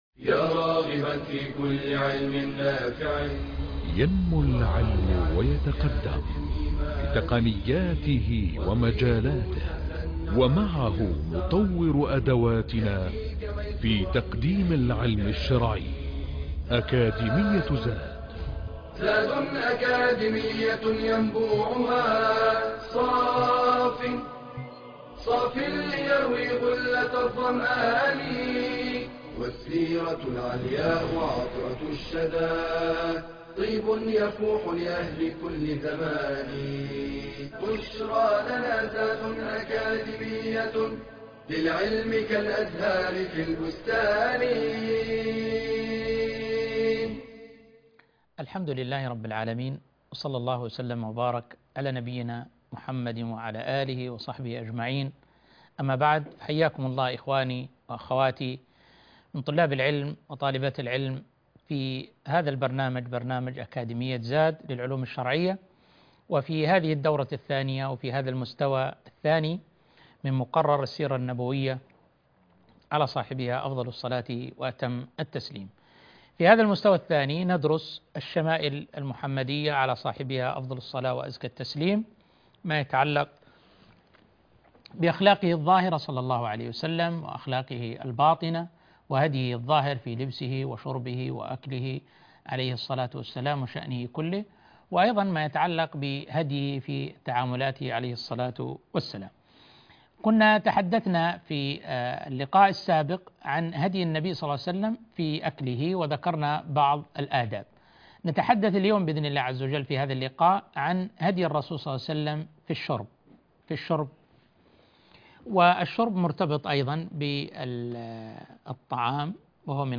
المحاضرة الثانية عشر - طعام وشراب النبي صلى الله عليه وسلم